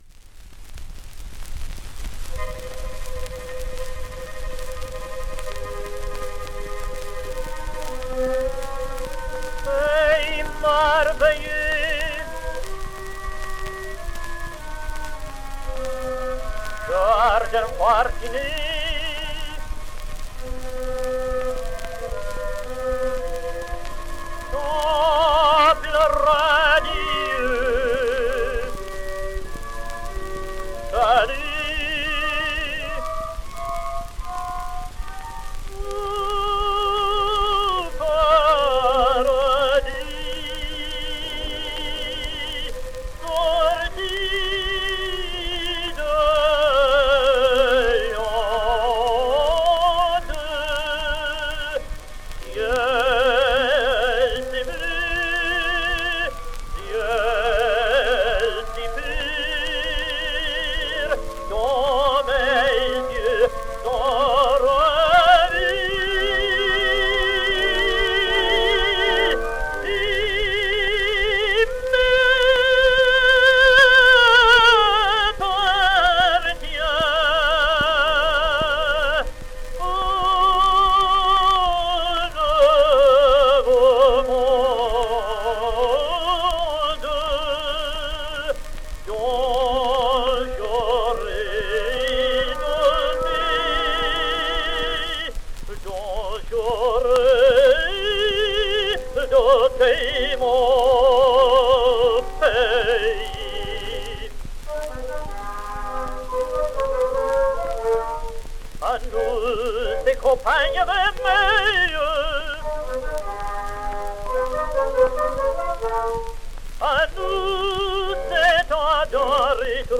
And he was something of an impostor, posing alternately as an Italian and a French tenor, as having toured with Fritz Kreisler, as having sung at Covent Garden, which is all untrue; further, as having studied with either Victor Maurel or Giovanni Sbriglia (the teacher of Pol Plançon, Jean and Édouard de Reszke), which is highly improbable; and as having toured also with Nellie Melba, which was highly imprecise (he only supported her in one concert in San Diego in February 1916).